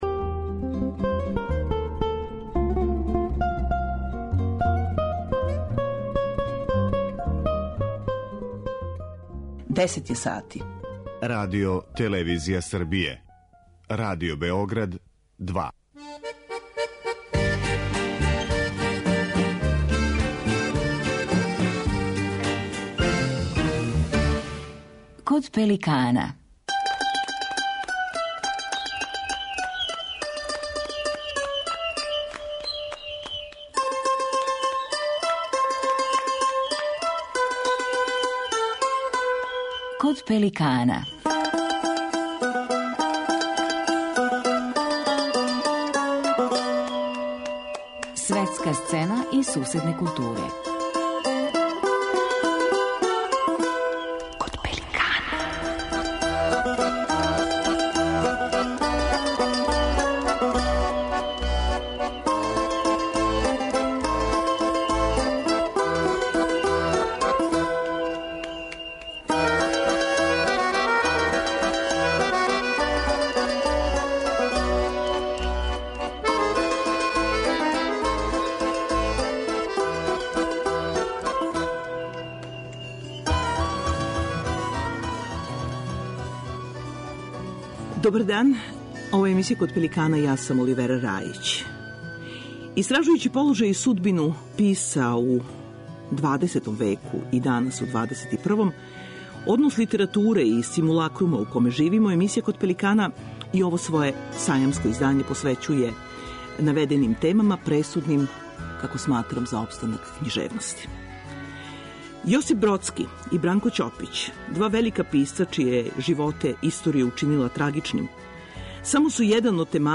Сајамско издање емисије